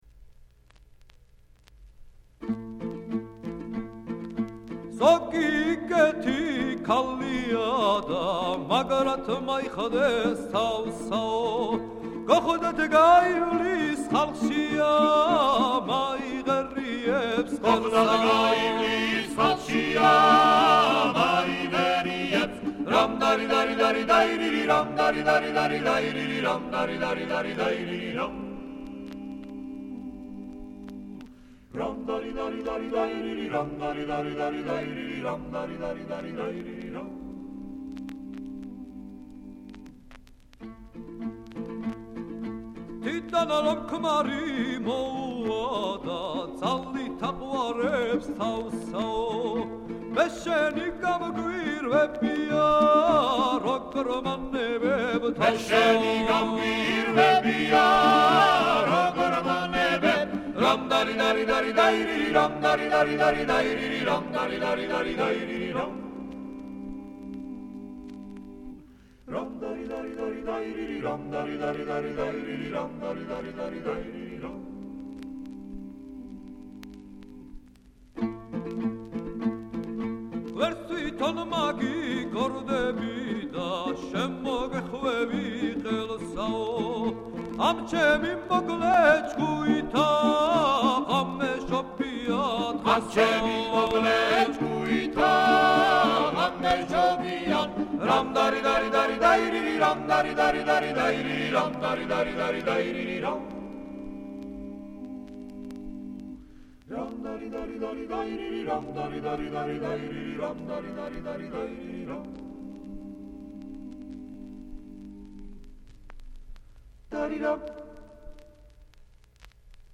Folk ensemble
Keywords: ქართული ხალხური სიმღერა